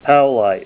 Help on Name Pronunciation: Name Pronunciation: Powellite + Pronunciation
Say POWELLITE Help on Synonym: Synonym: ICSD 60552   PDF 29-351